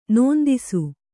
♪ nōndisu